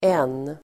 Uttal: [en:]